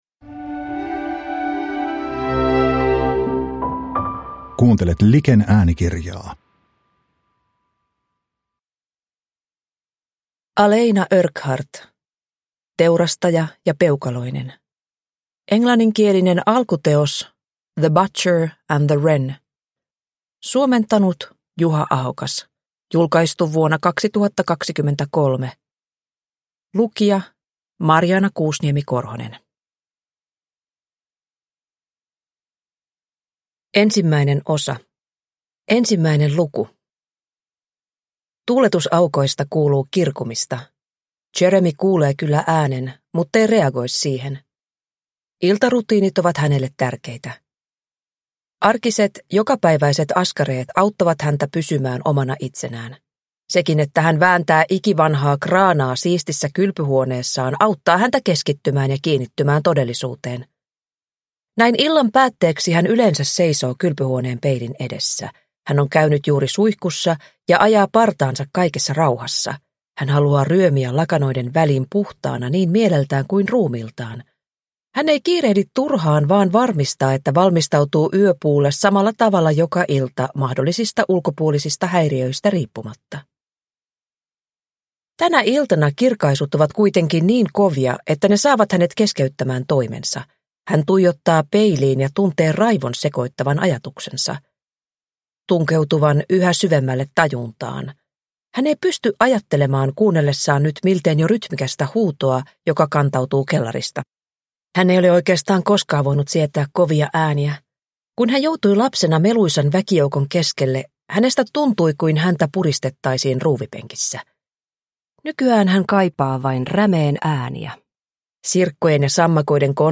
Teurastaja ja Peukaloinen – Ljudbok – Laddas ner